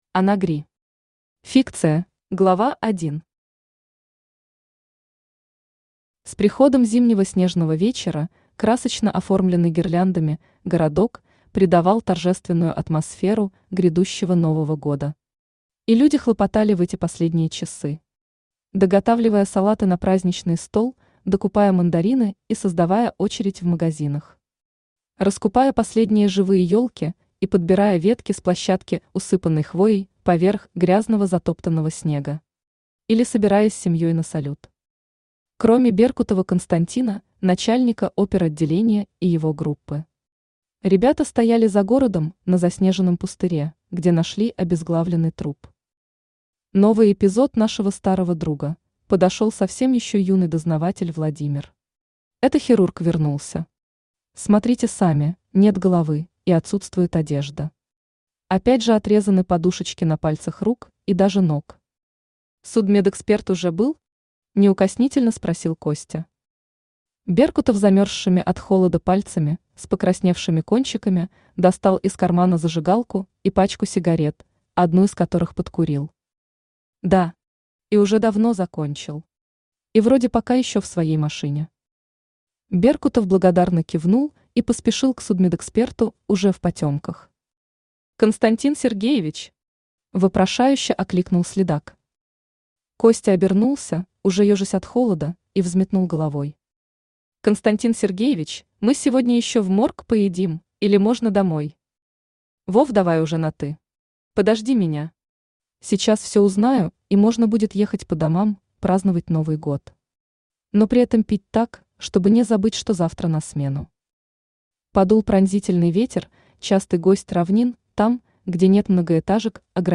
Аудиокнига Фикция | Библиотека аудиокниг
Aудиокнига Фикция Автор Ана Гри Читает аудиокнигу Авточтец ЛитРес.